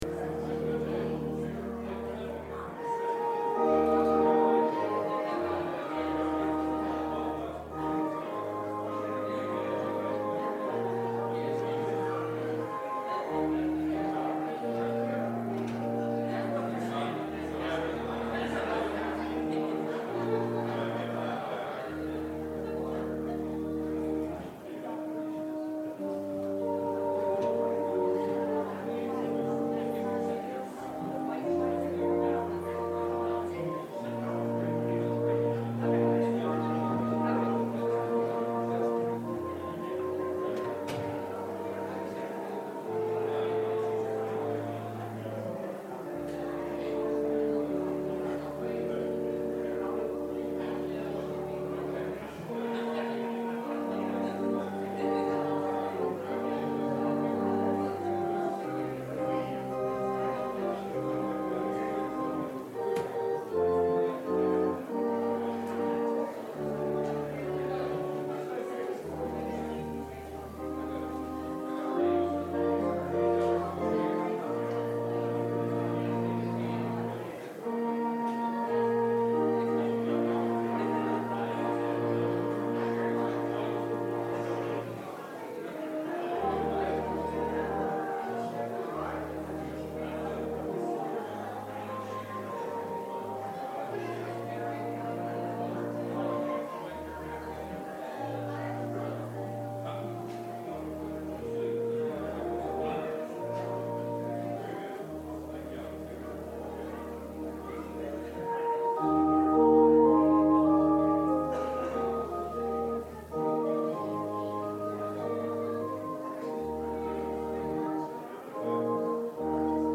Passage: John 6:32-40 Service Type: Sunday Worship